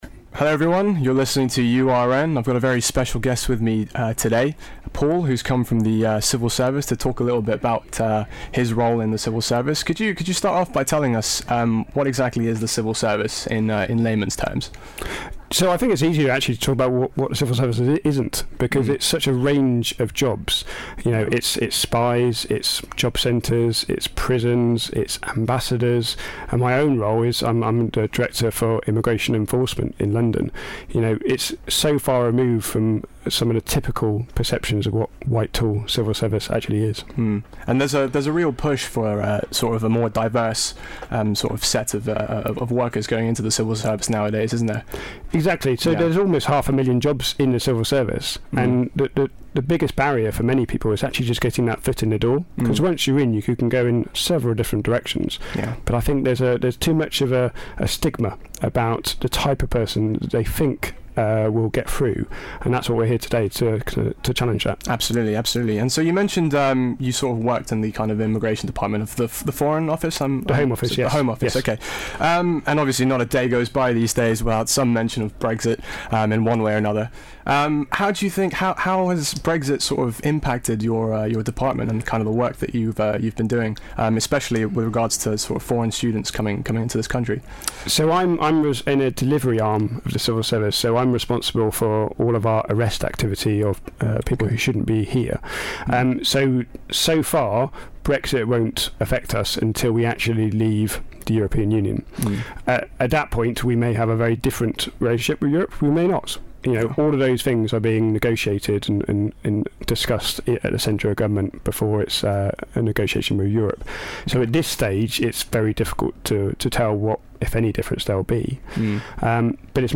Interview: The Civil Service